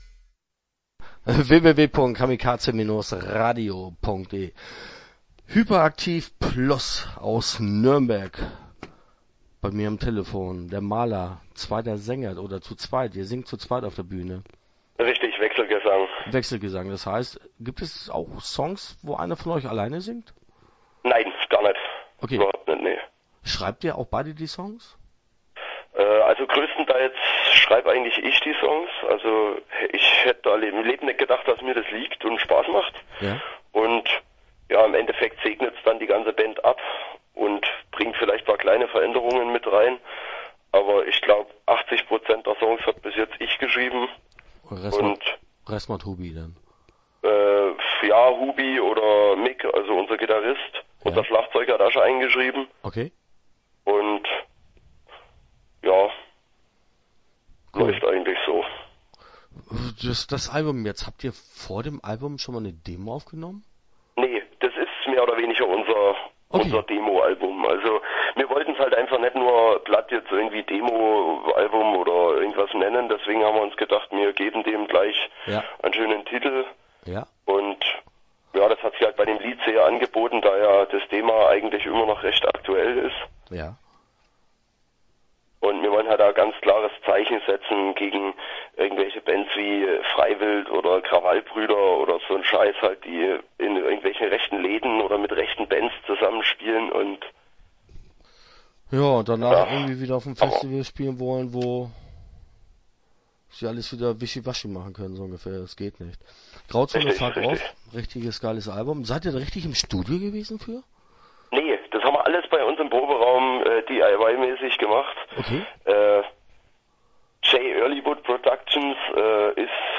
Start » Interviews » HypaAktiv+